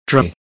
us_phonetics_sound_dra_2023feb.mp3